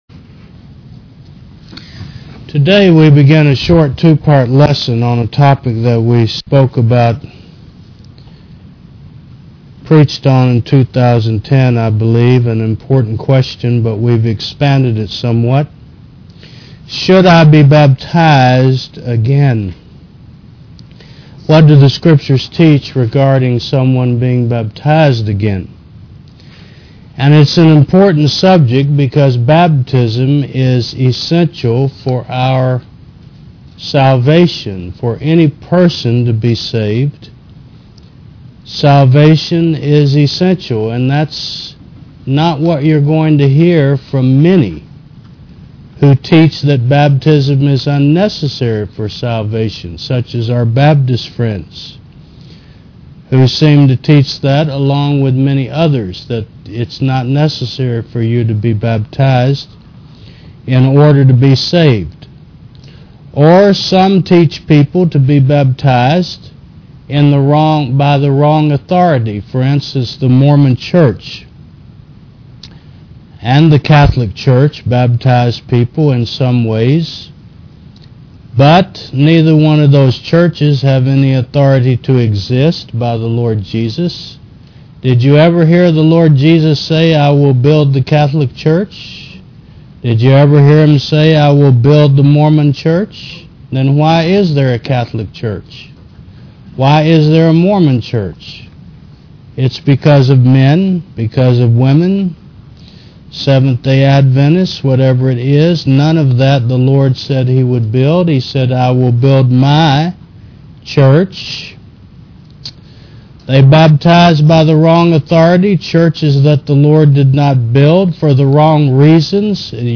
Should I Be Baptized Again? v2 Service Type: Sun. 11 AM Preacher